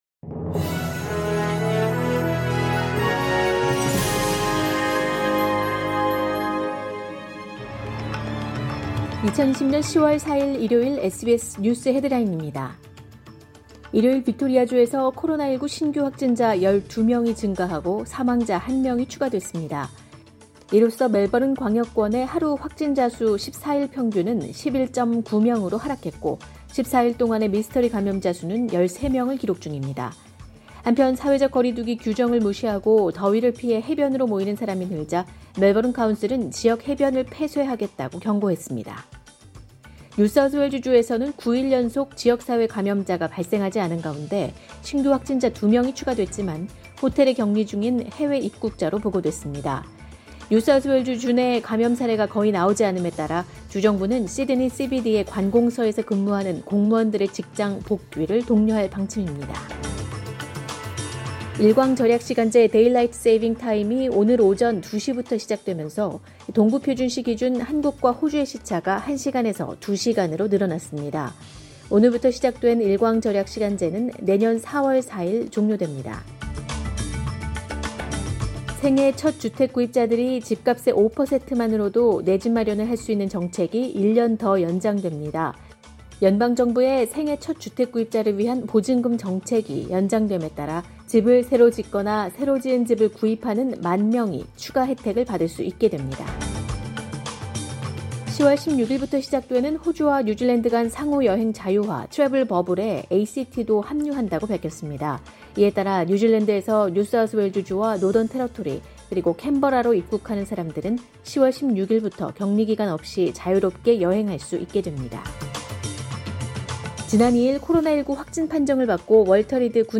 SBS News Headlines…2020년 10월 4일 오전 주요 뉴스
2020년 10월 4일 일요일 오전의 SBS 뉴스 헤드라인입니다.